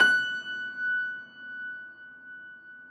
53g-pno20-F4.wav